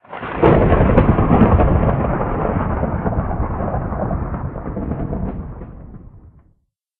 Minecraft Version Minecraft Version latest Latest Release | Latest Snapshot latest / assets / minecraft / sounds / ambient / weather / thunder1.ogg Compare With Compare With Latest Release | Latest Snapshot
thunder1.ogg